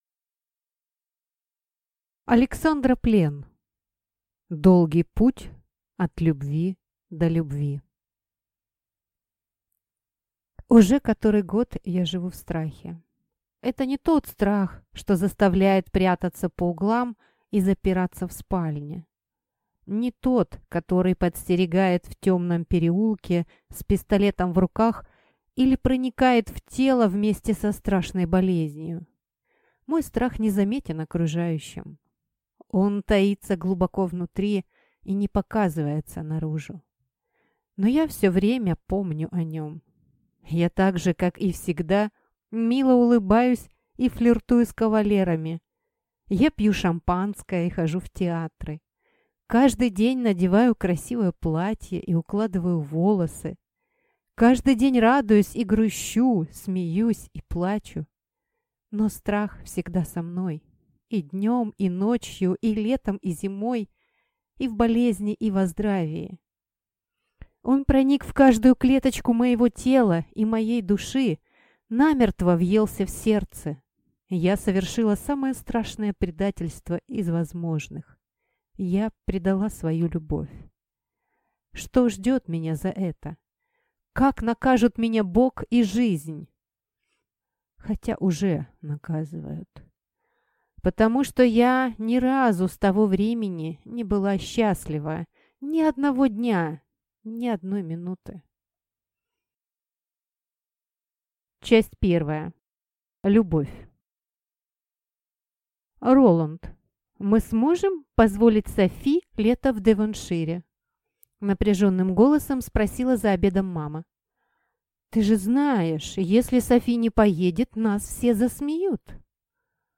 Аудиокнига Долгий путь от любви до любви | Библиотека аудиокниг